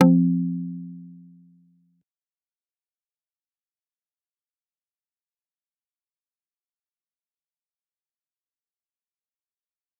G_Kalimba-F3-pp.wav